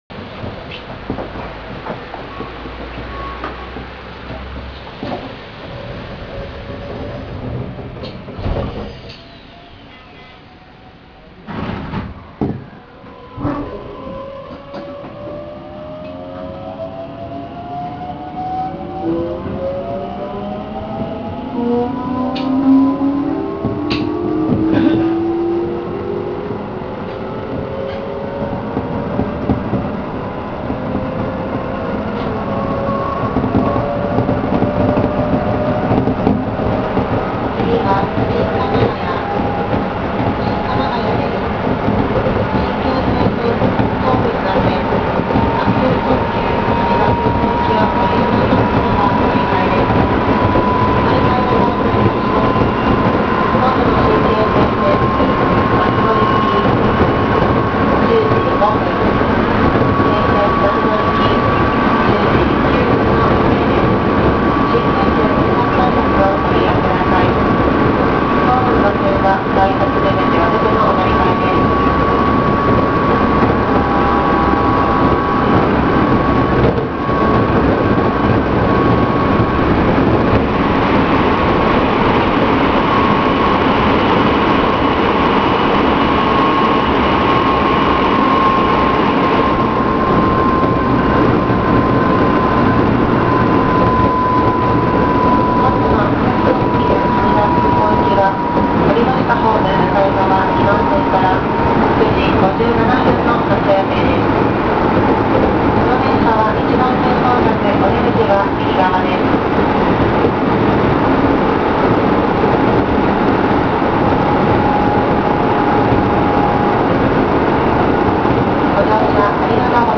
・3700形（６次車〜）走行音
【北総線】西白井〜新鎌ヶ谷（3分13秒：1.02MB）
６次車以降でも音自体に違いはないものの、ドアエンジンは変更されました。そのため、ドアの音がやや静かになっています。